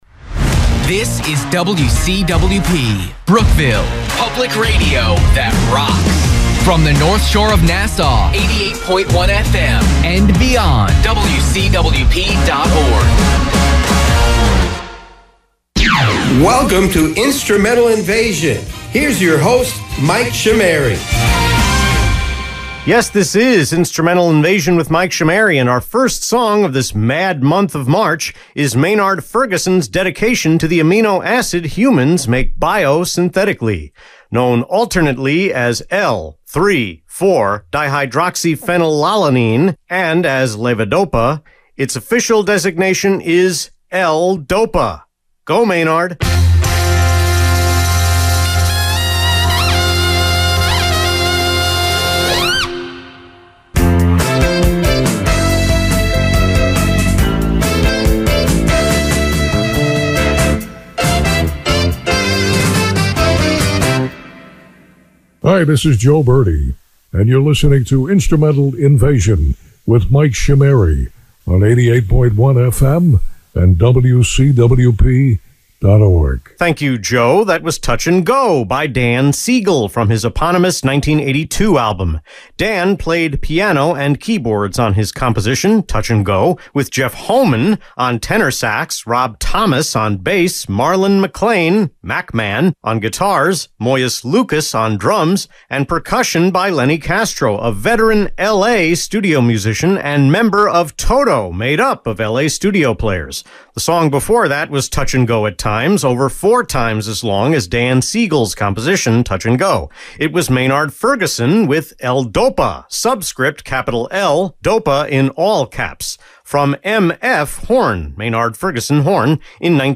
The March 1 Instrumental Invasion on WCWP was recorded and mixed entirely on January 9.
So, I partially redid hour 2 talk breaks at a slower pace, except for two that were redone entirely.